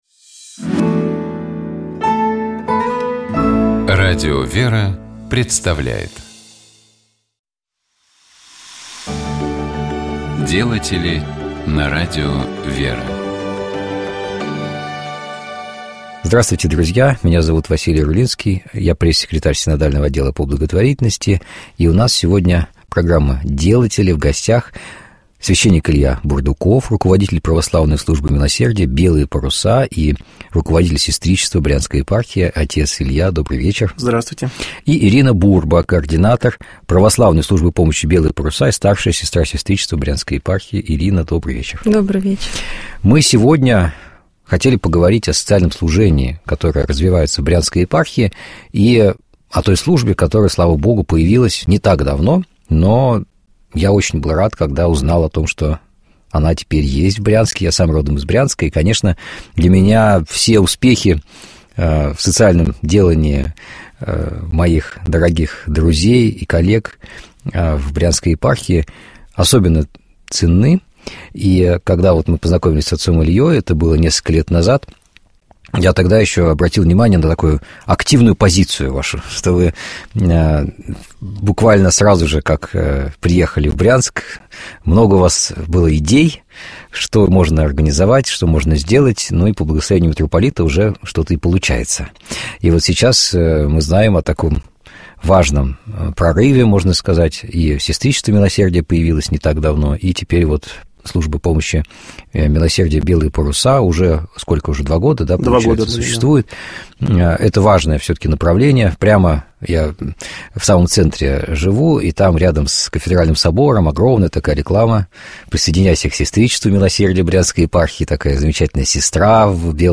В нашей студии